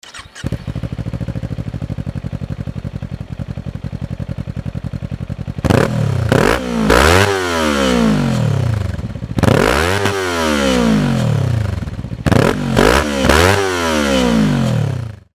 まずは排気音を収録してきたのでお聞き下さい。
ノーマルマフラー